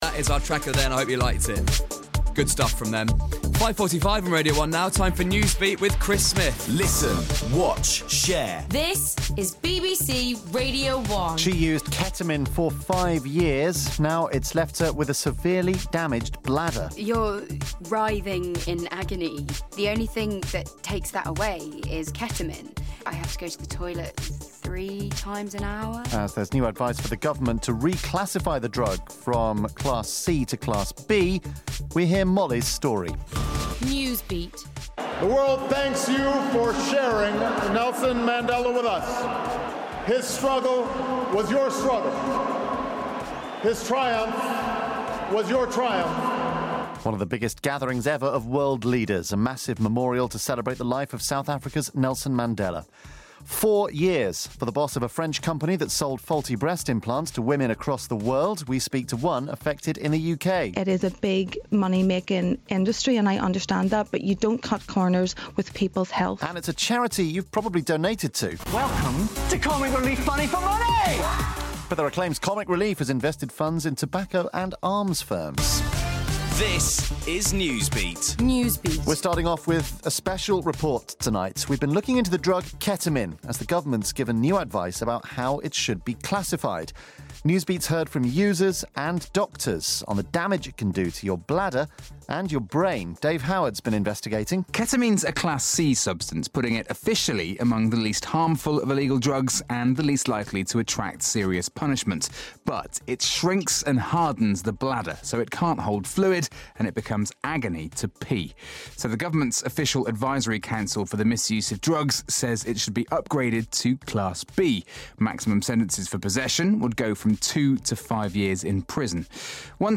We've been speaking to long-term users in Bristol about how the drug affects them.